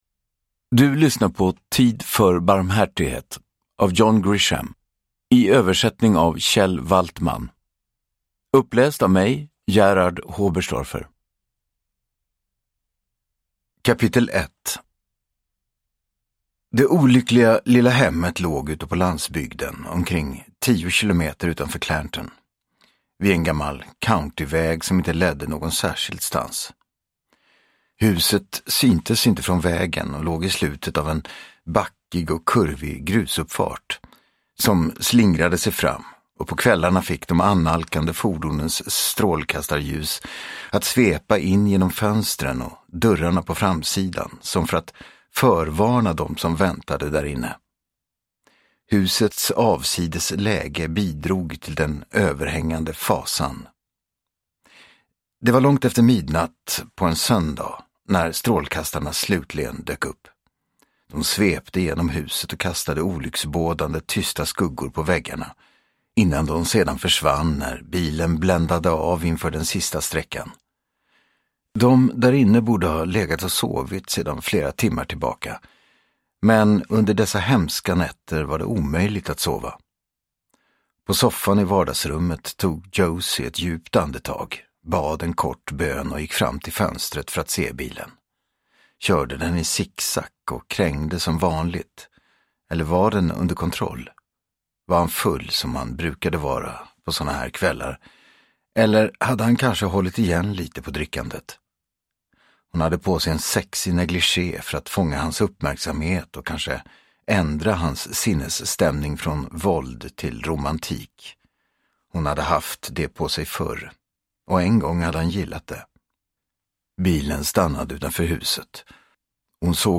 Uppläsare: Gerhard Hoberstorfer